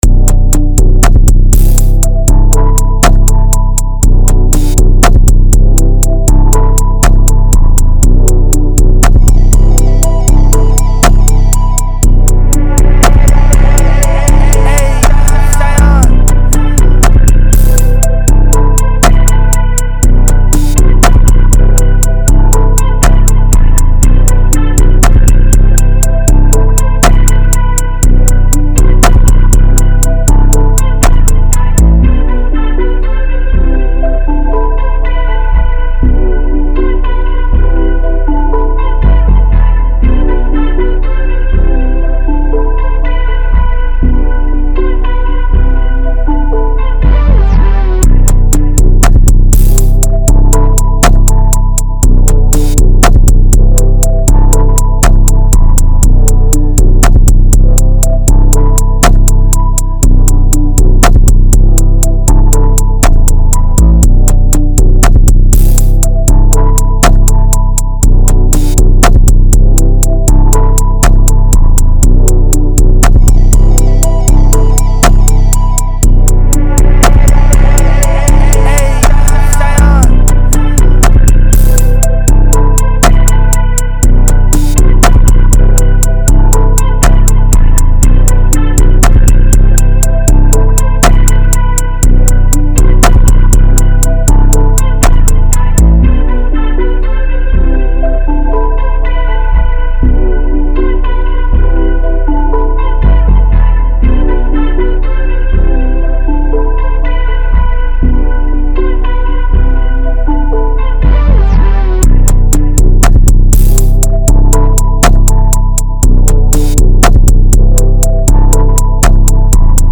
120 E Minor